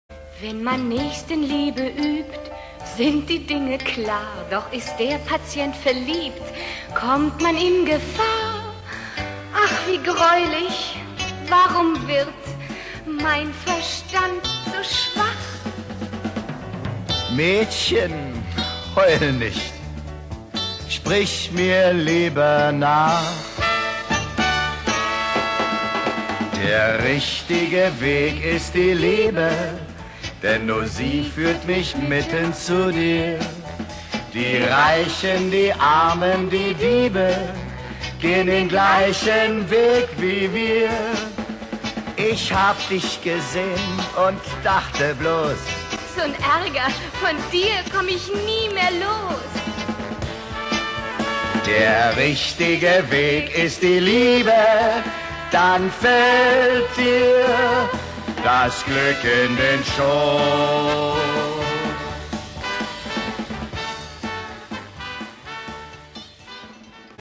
Musical für den Rundfunk (Originalhörspiel)
Besetzung: 9 Darsteller (Sprecher/Sänger)